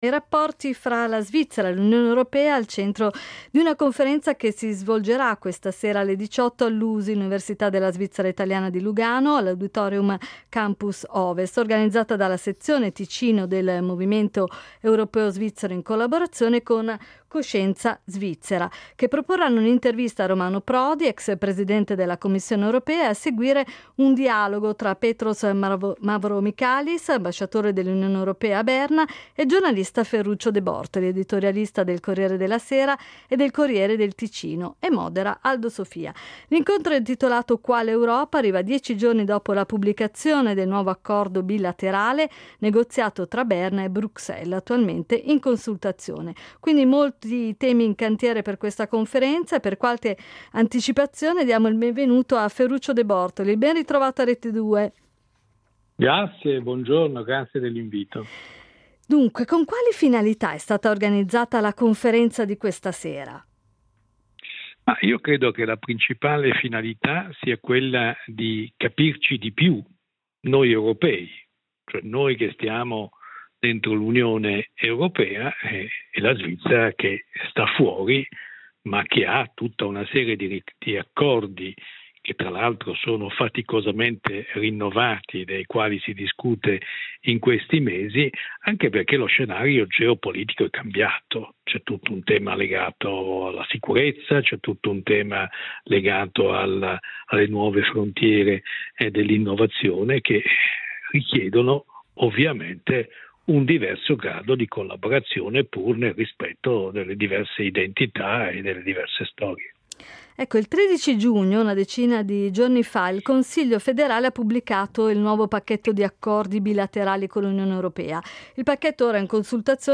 Per approfondire gli aspetti di questo accordo abbiamo avuto ospite Ferruccio de Bortoli.